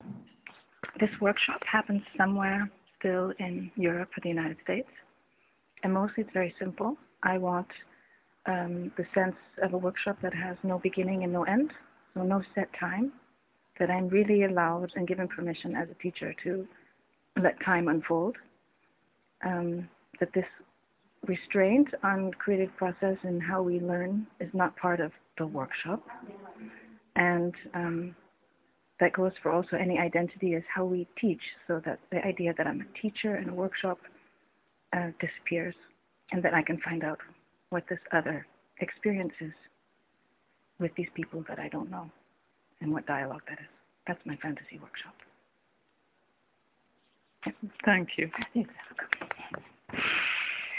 I do short interview of the teacher , with one question: Can you say to me which fantasy of workshop you have?